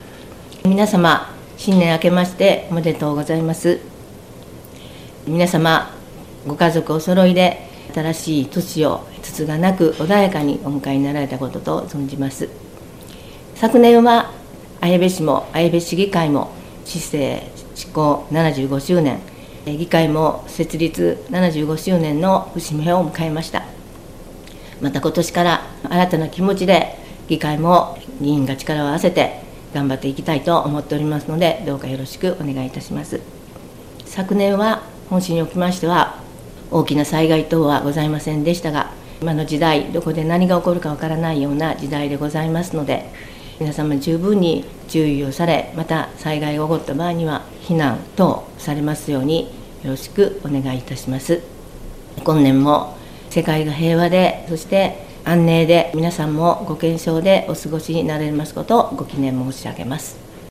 2026年議長年頭あいさつ | 綾部市